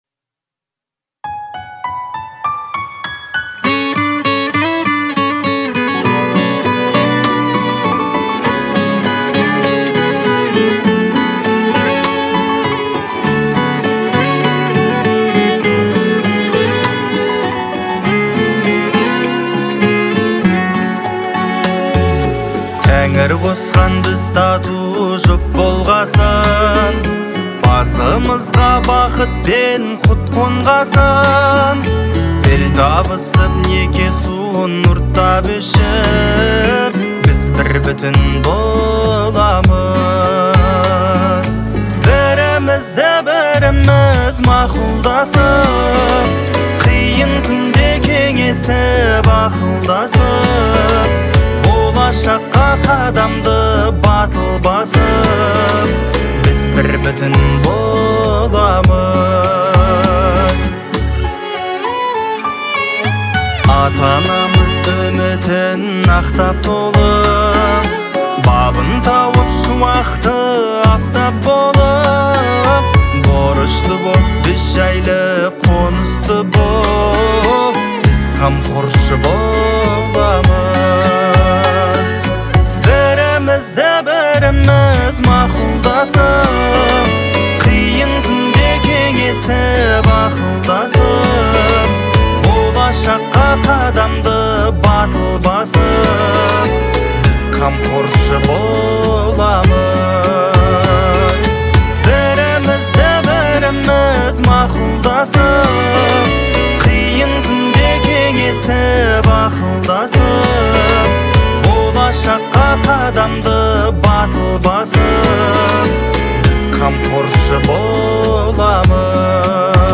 относится к жанру поп и наполнена позитивным настроением.